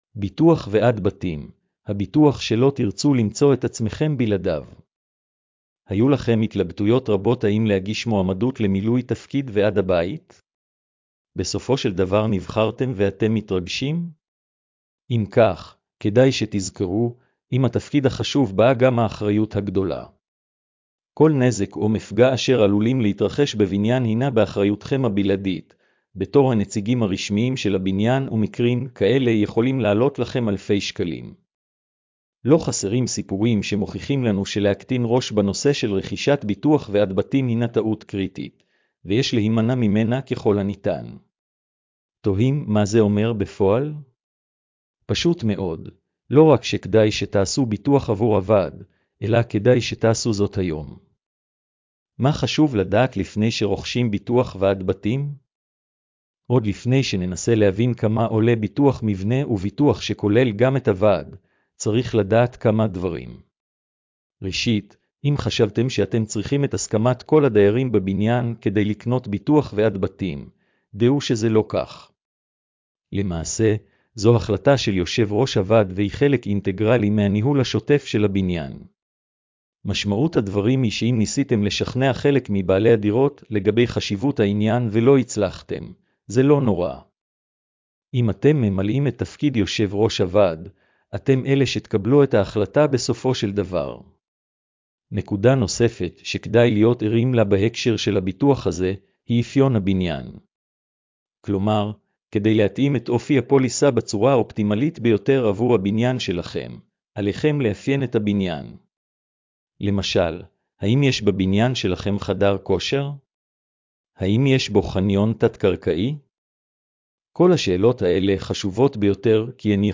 הקראת המאמר לבעלי מוגבלויות: